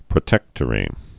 (prə-tĕktə-rē)